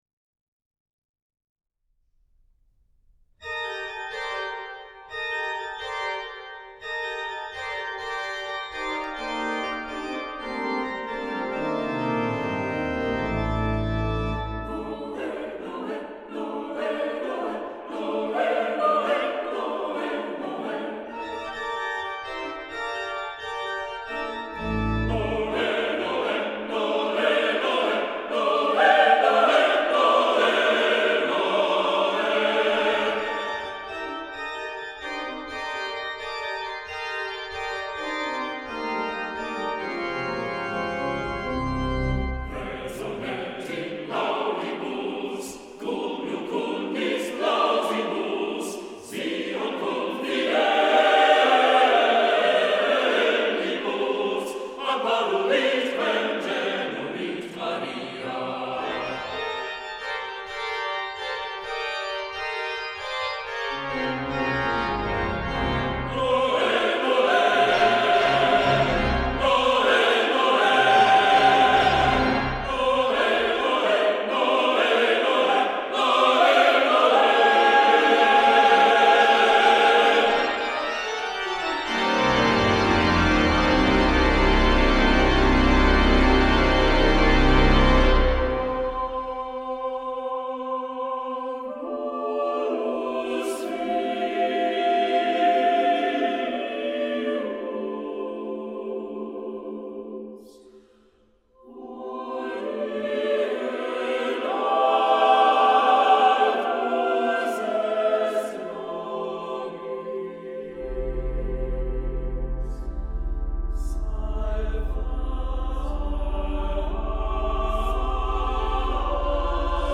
Voicing: SATB divisi and Organ